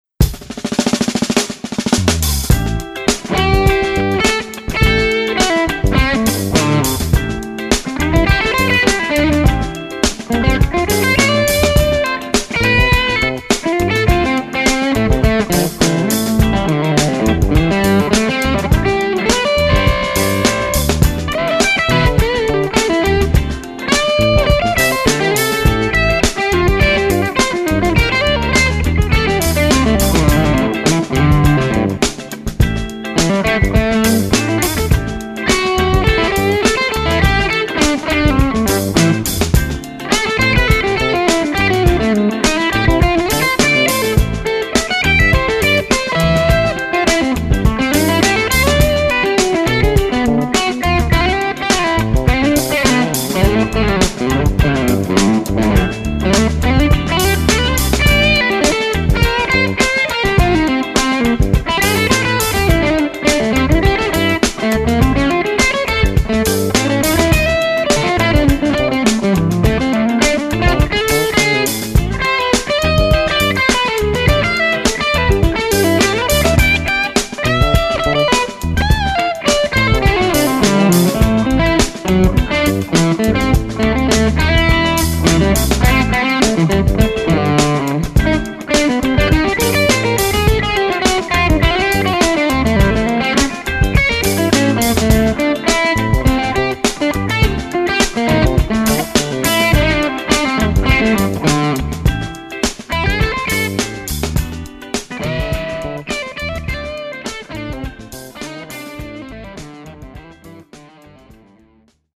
Sorry for crappy playing.